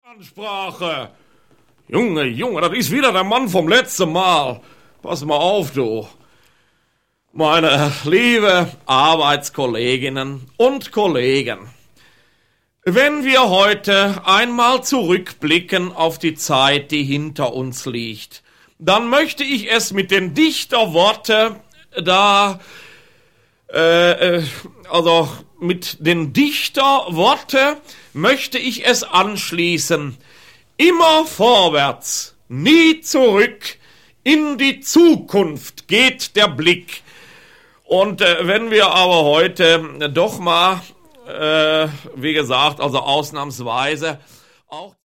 Jürgen von Manger (Sprecher)
Jürgen von Manger, 1923 in Koblenz geboren, begann seine Karriere als Schauspieler nach dem zweiten Weltkrieg. 1961 schuf er die Figur des »Adolf Tegtmeier«, der in der Sprache des Ruhrgebietes von den Theater- und Kleinkunstbühnen der Republik herab für das Ruhrgebiet warb, indem er mit seinem gesunden Menschenverstand über das Leben philosophierte oder Geschichten von nebenan erzählte.